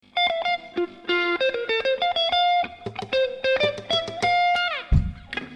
２００２年 Italy